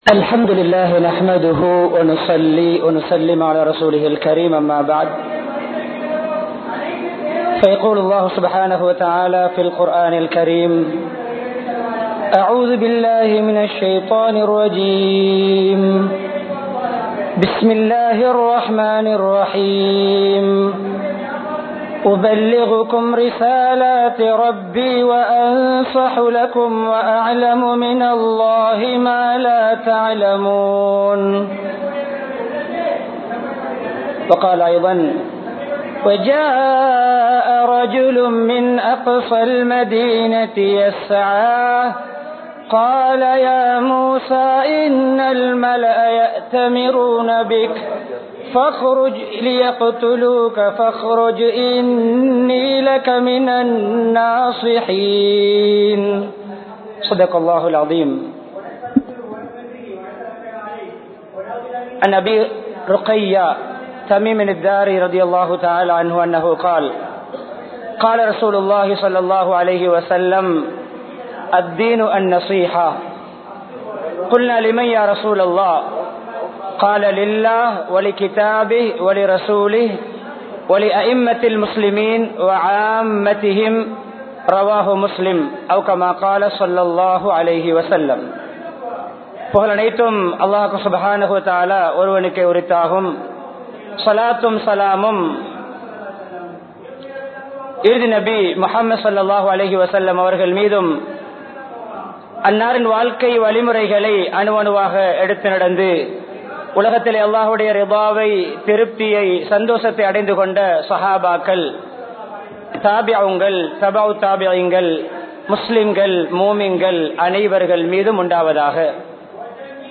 பிறரது நலம் பேணுவோம் | Audio Bayans | All Ceylon Muslim Youth Community | Addalaichenai
Kollupitty Jumua Masjith